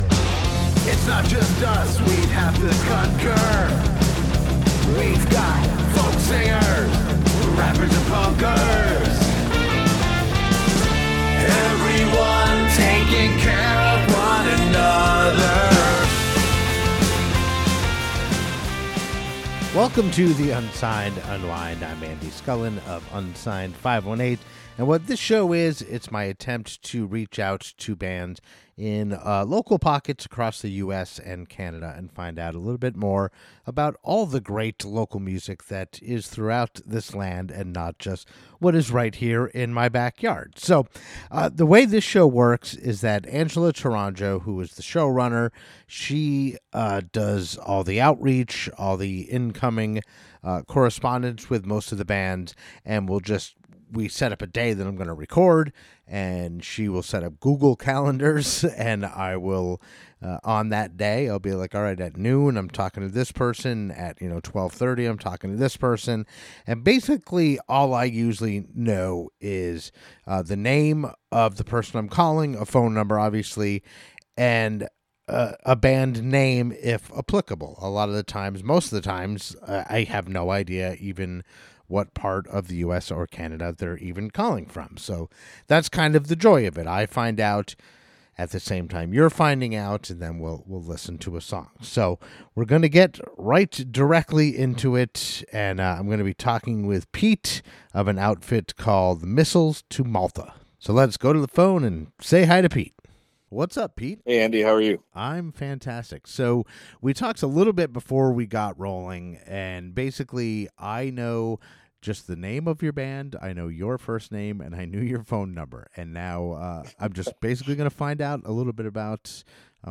This show is an attempt to gather great local music from all over the US and Canada, have a brief conversation with the band/musician and play one of their songs. My goal is that local-music enthusiasts, such as myself, can discover great local music that otherwise may have remained hidden to them.